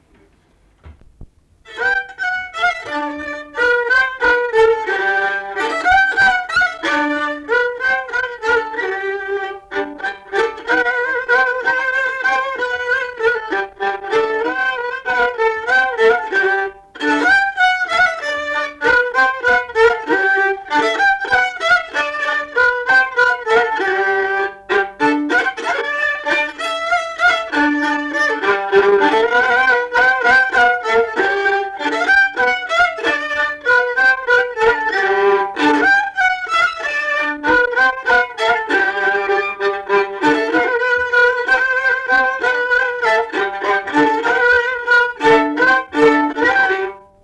šokis
Erdvinė aprėptis Samantonys
Atlikimo pubūdis instrumentinis
Instrumentas smuikas